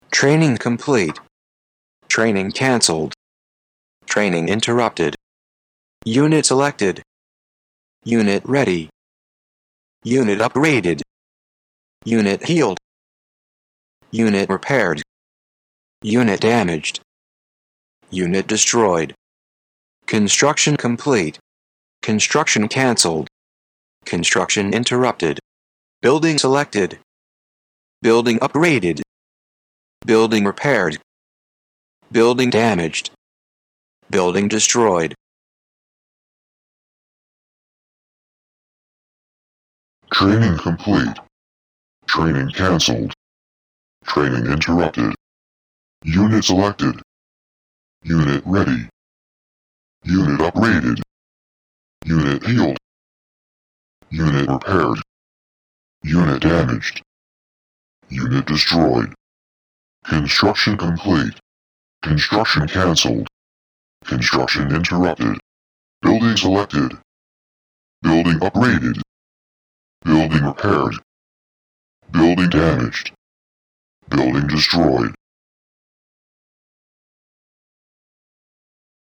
RTS commands and reports
Some common RTS commands and reports I have recorded them in two voices a normal human male voice and a very deep voice as I thought it would be useful to use for human Vs Beast type games